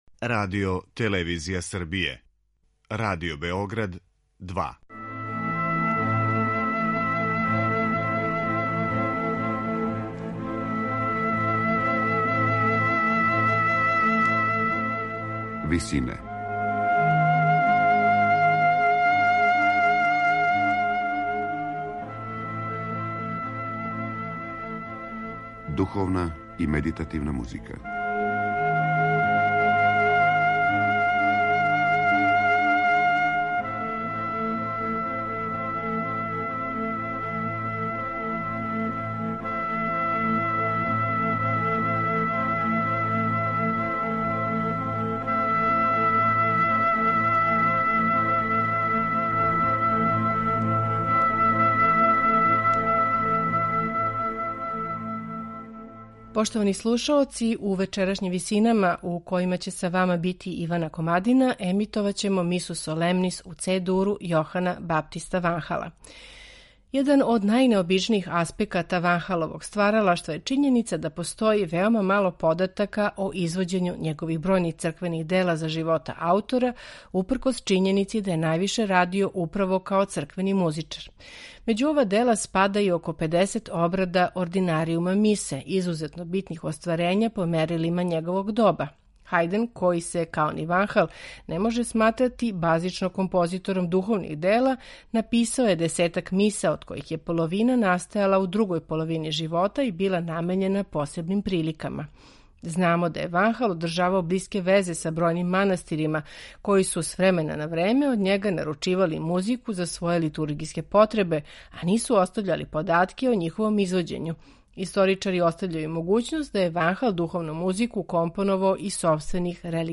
сопран
тенор
баритон
оргуље